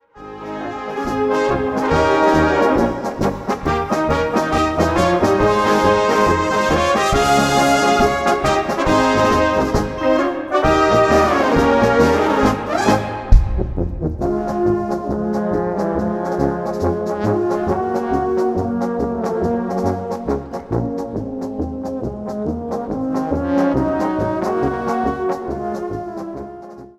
Live-Album zum Jubiläum
Blasmusik
Blasmusik auf höchstem Niveau.